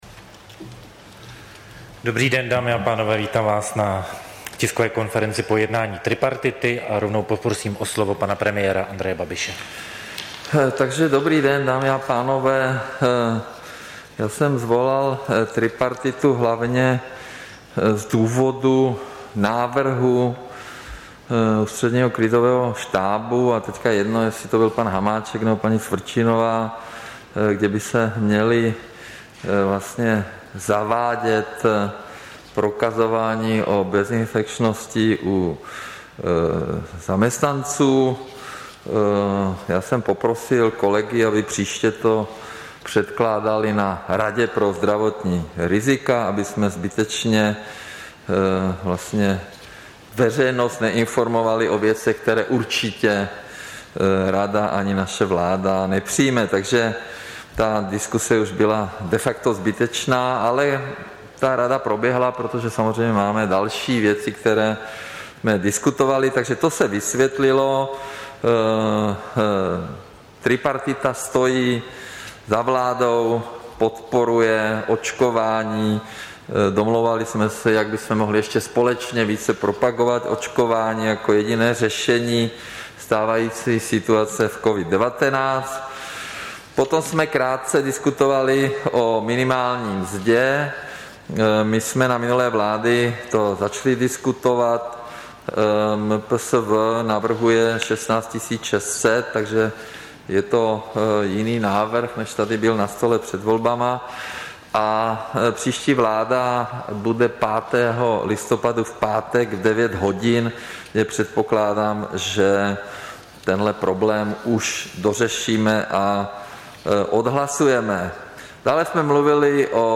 Tisková konference po mimořádném jednání tripartity, 27. října 2021